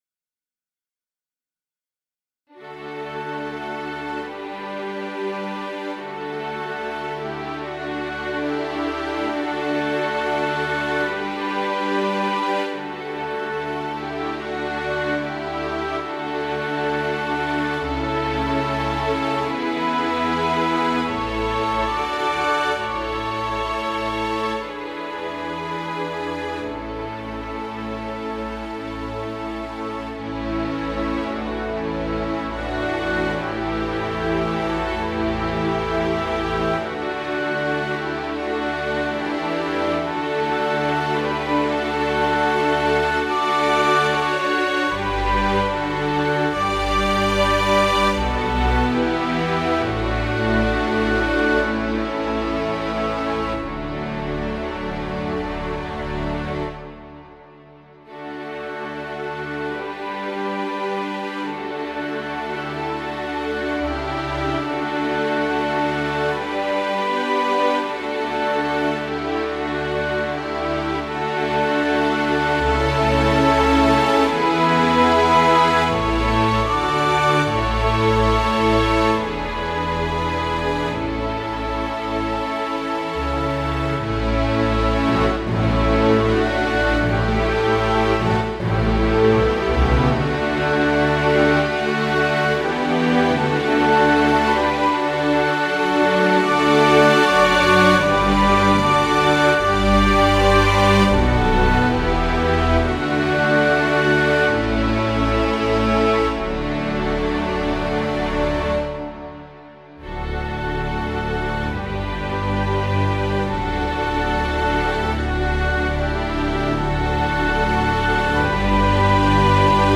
Nächtliche „After-Weihnachtsmarkt“-Spontan-Improvisation mit einer Extraportion Pathos.
🎄🎁 Nachtrag zum Sound: Ein Orchesterpatch vom Nautilus, gelayered (schreibt man das so?) mit Strings vom NS2.